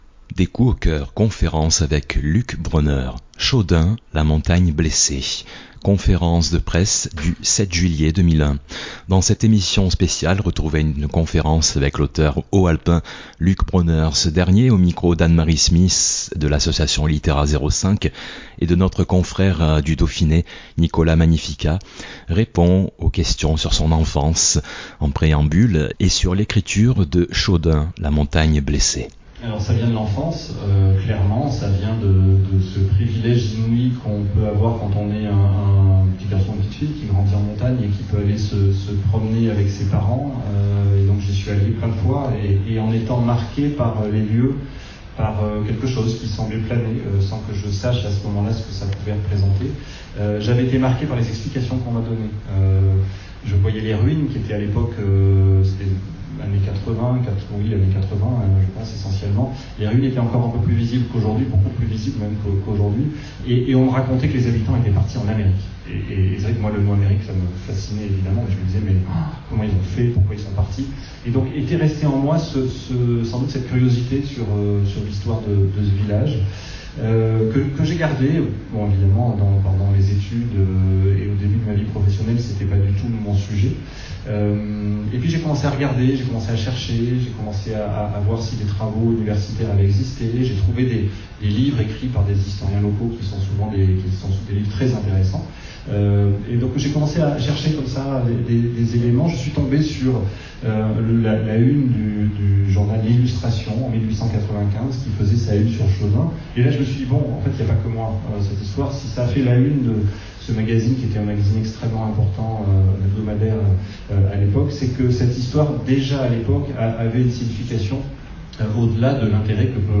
Dans cette émission spéciale retrouvez une conférence
Conférence du 7 juillet 2021.